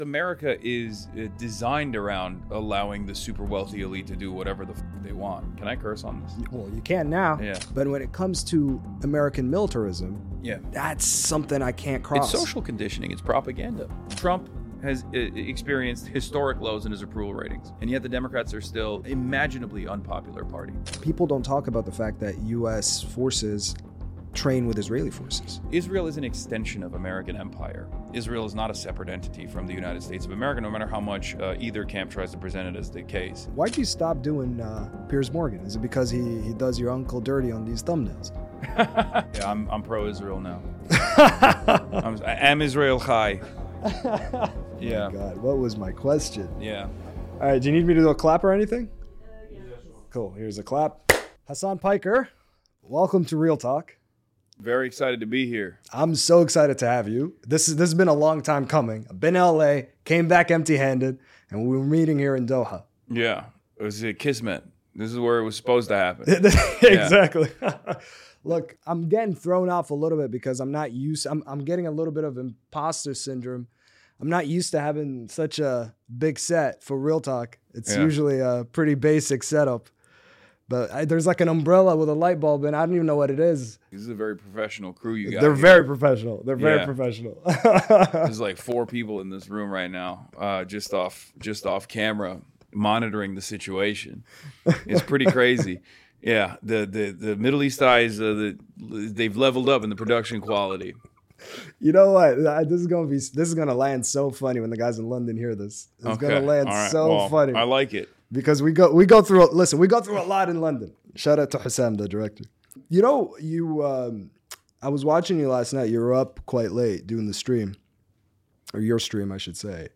The left-wing commentator reflects on the personal and professional cost of speaking out, the limits of platform censorship, and what shaped his understanding of US power and hegemony.